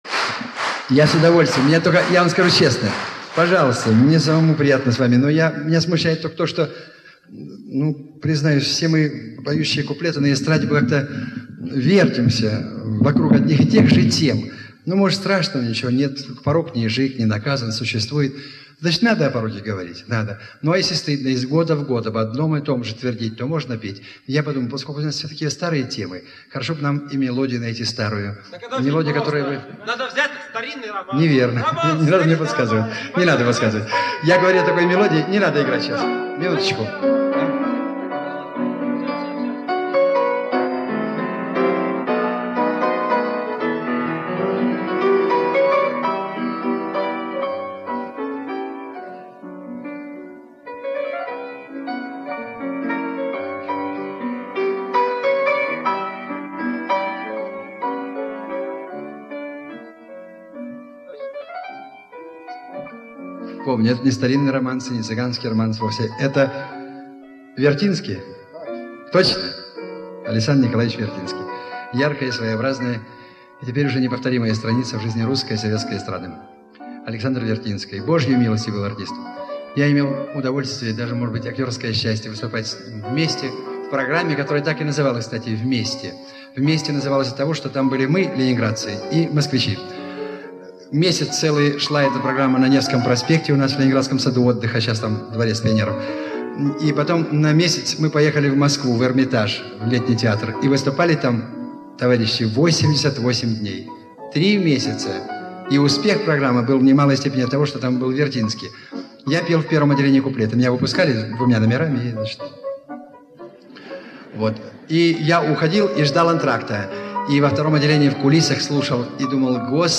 сатирических куплетов